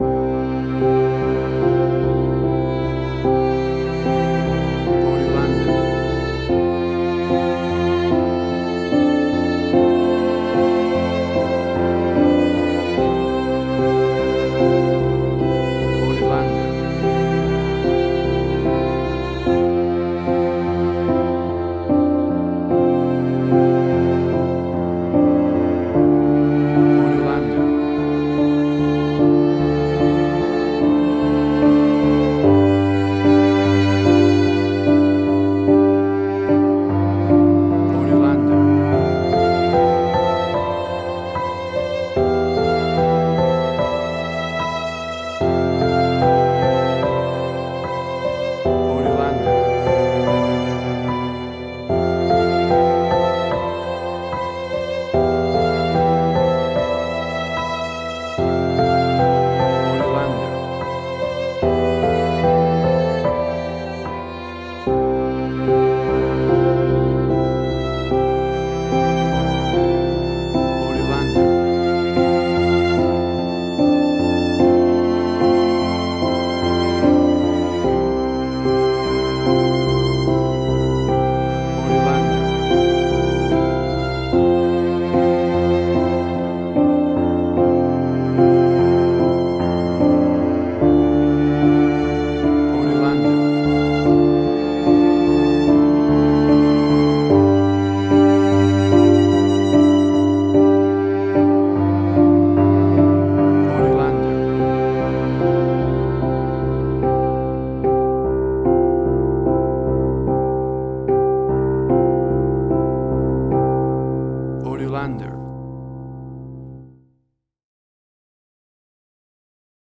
Suspense, Drama, Quirky, Emotional.
WAV Sample Rate: 16-Bit stereo, 44.1 kHz
Tempo (BPM): 73